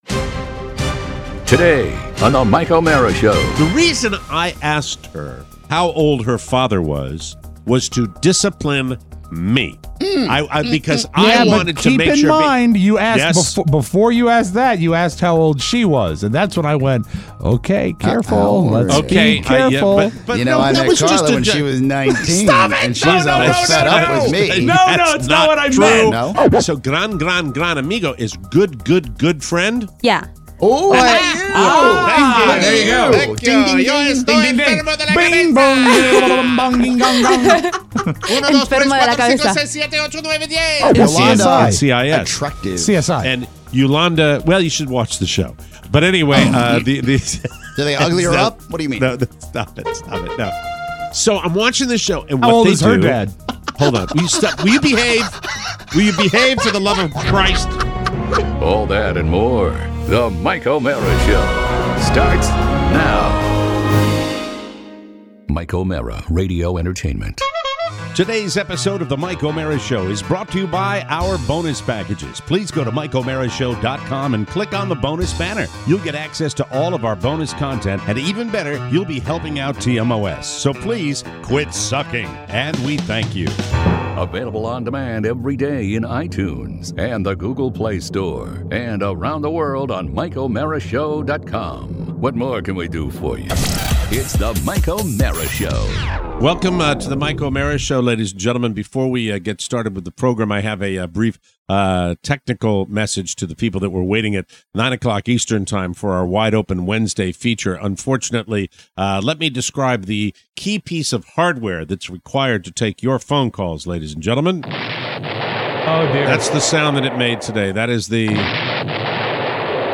We are joined in studio